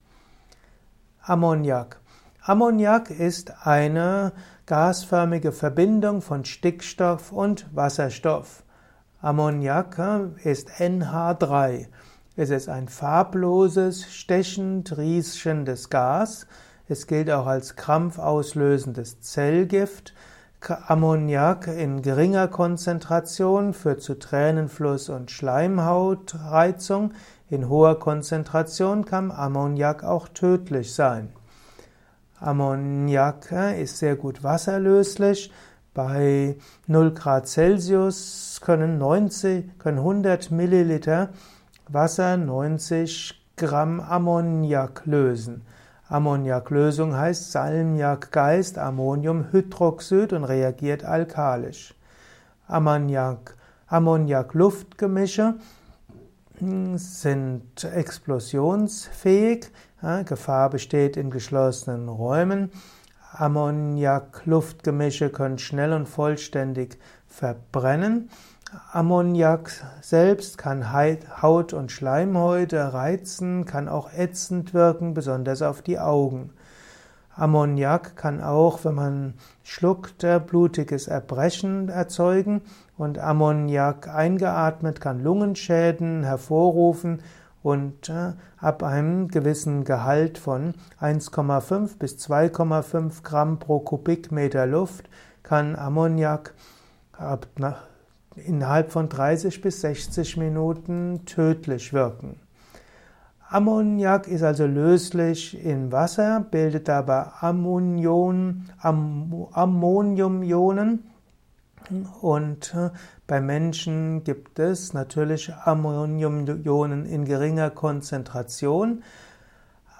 Simple und kompakte Infos zum Thema in diesem Kurzvortrag